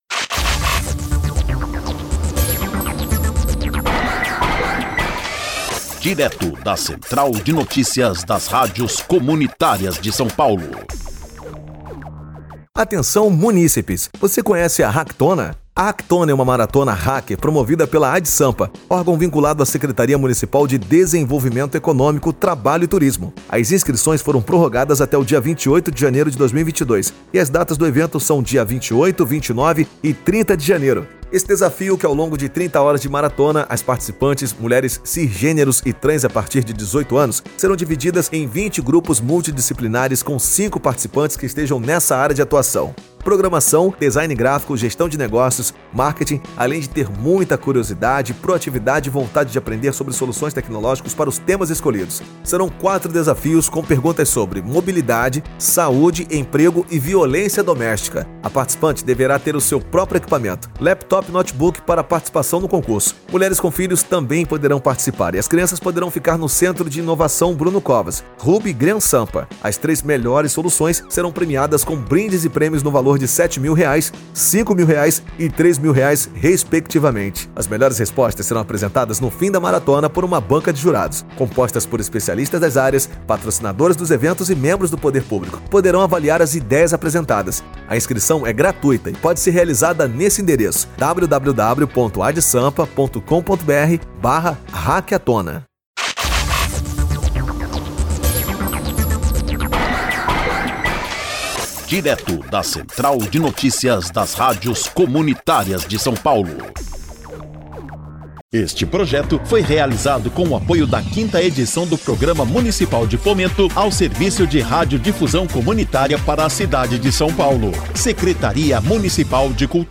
INFORMATIVO: MARATONA HACKER, HACKTONA